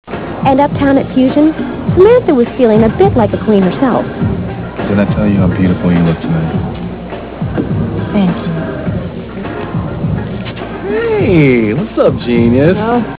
Comment: rock